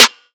DDW Snare 7.wav